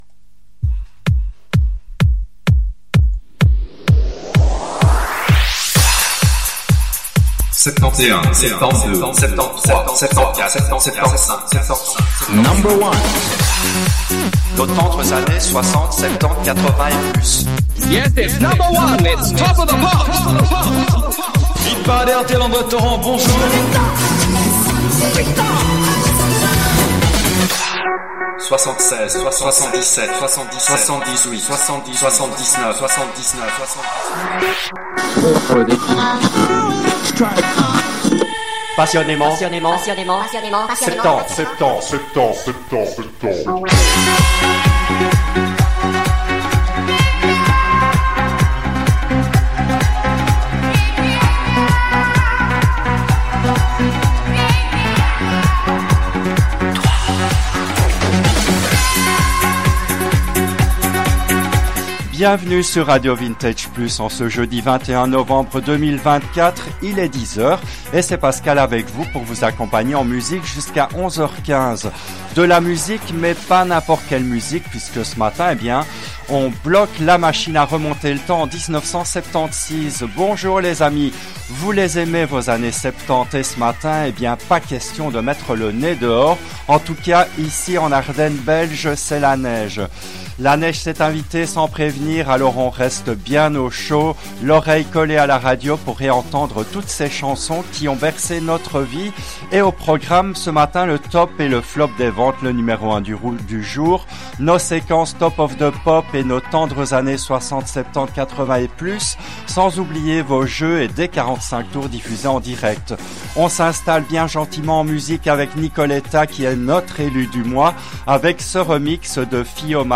L’émission a été diffusée en direct le jeudi 21 novembre 2024 à 10h depuis les studios belges de RADIO RV+.